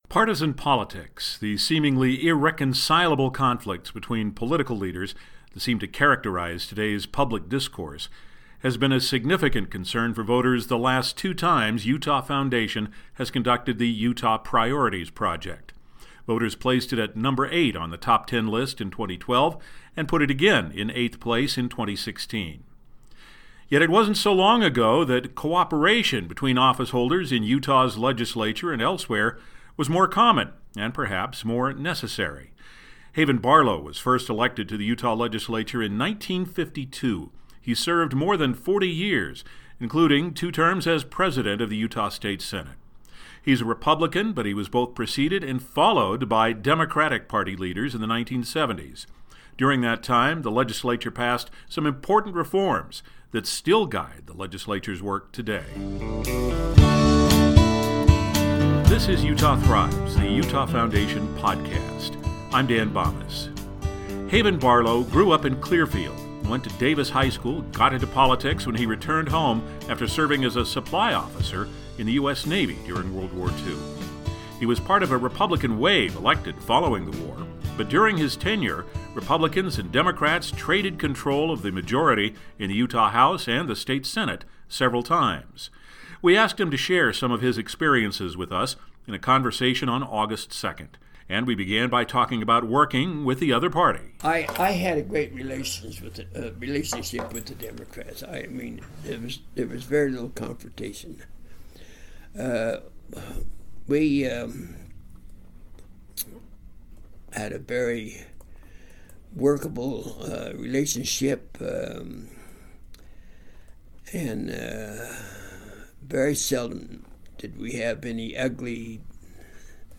We spoke to Haven Barlow at his office in Layton on August 2, 2016.